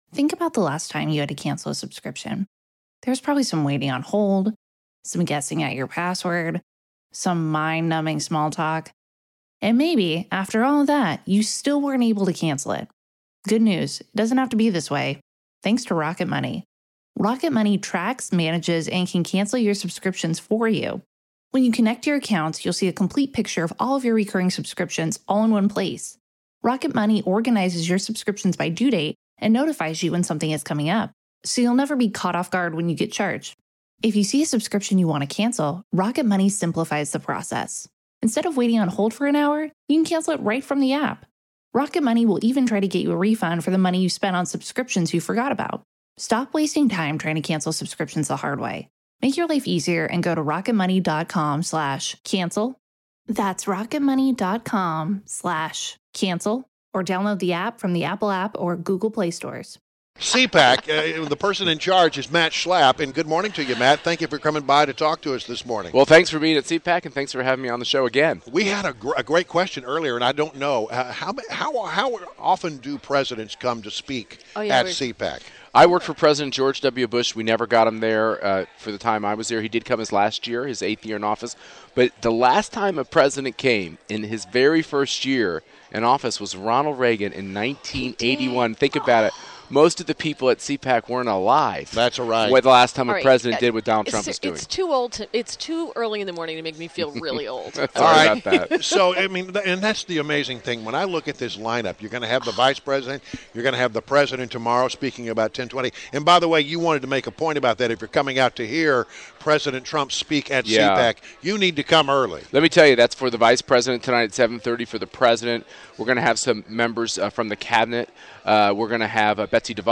WMAL Interview - MATT SCHLAPP - 02.23.17
INTERVIEW – MATT SCHLAPP – CHAIRMAN, AMERICAN CONSERVATIVE UNION, the organization that hosts and organizes CPAC —PREVIEWED CPAC’s events and highlights.